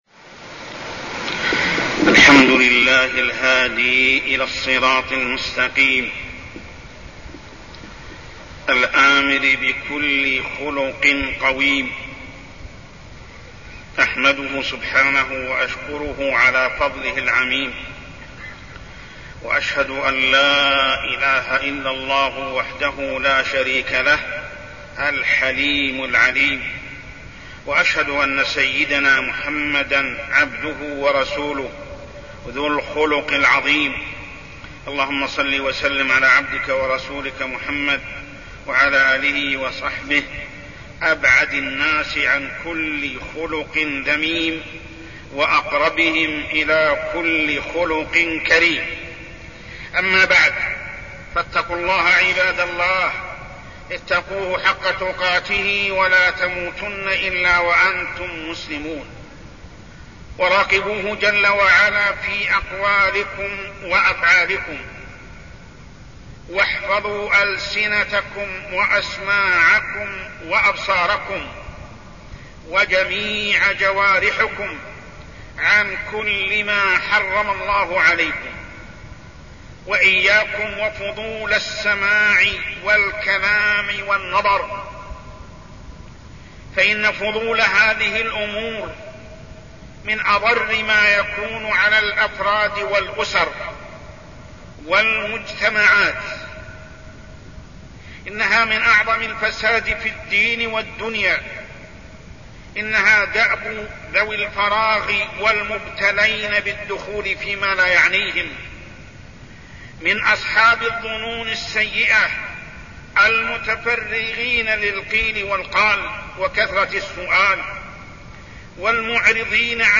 تاريخ النشر ١٣ جمادى الآخرة ١٤١٤ هـ المكان: المسجد الحرام الشيخ: محمد بن عبد الله السبيل محمد بن عبد الله السبيل حفظ الجوارح